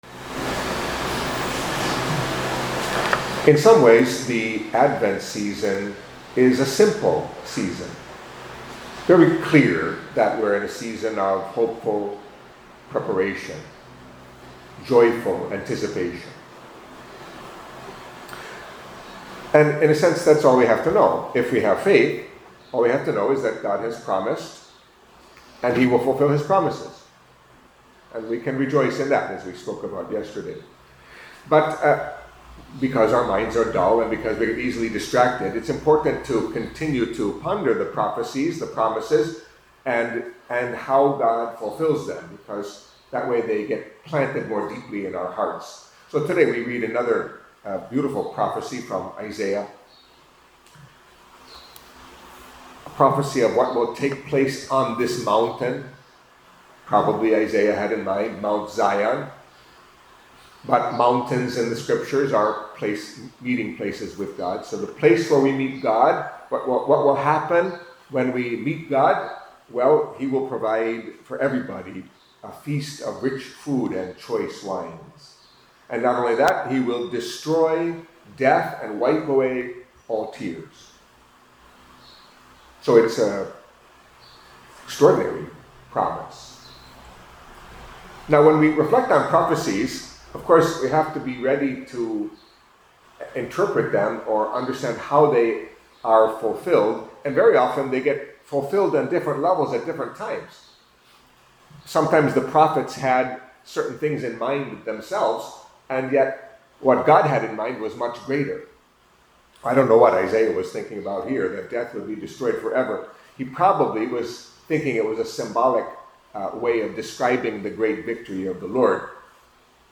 Catholic Mass homily for Wednesday of the First Week of Advent